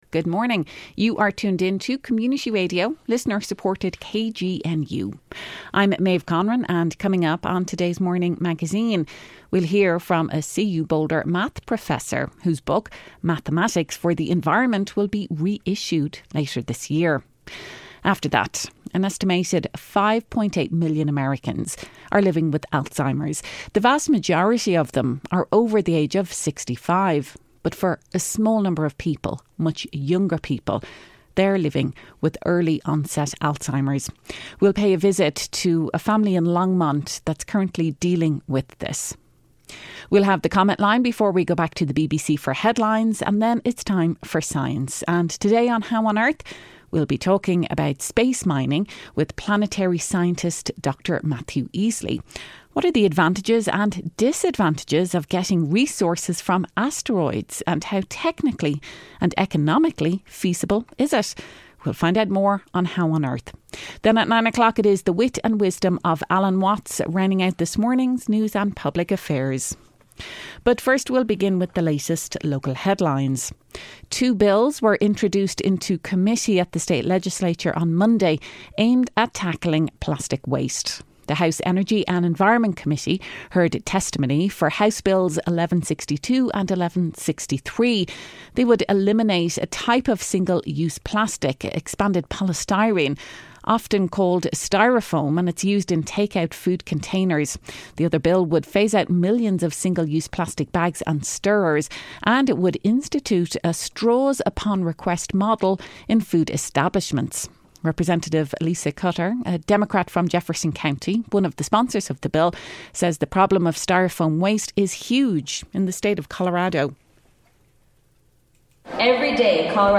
Then, a local family talks about their struggles with early-onset Alzheimers followed by the comment line.
The Morning Magazine features local news headlines, stories and features and broadcasts on KGNU Monday through Friday 8.04-8.30am.